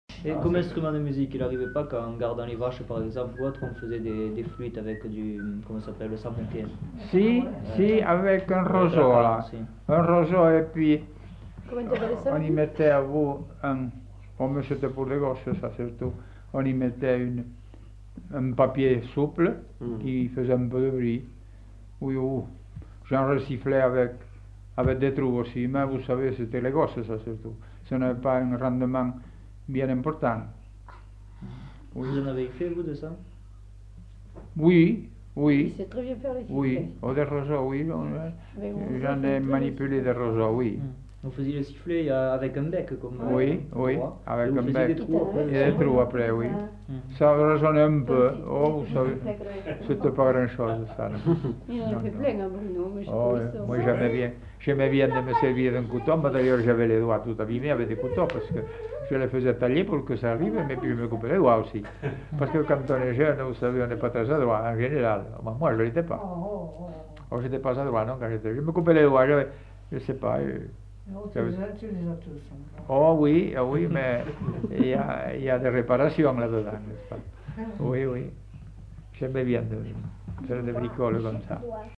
Genre : témoignage thématique
Instrument de musique : sifflet végétal